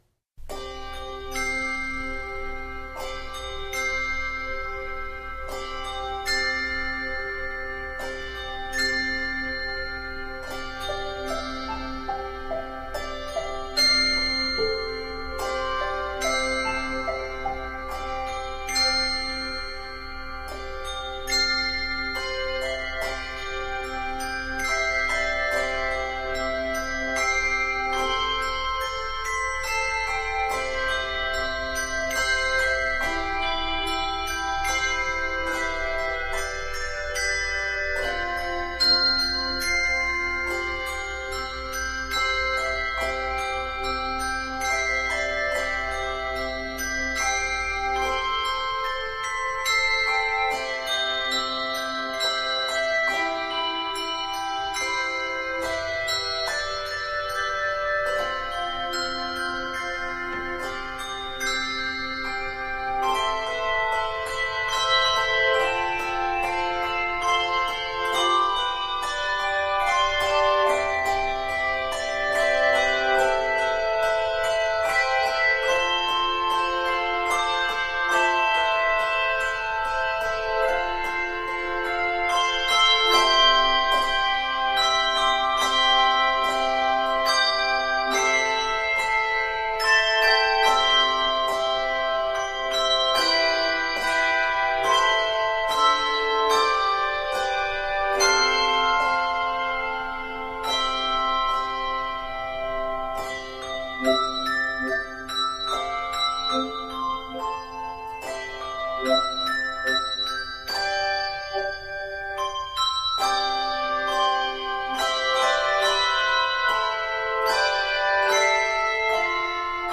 3 octave handbells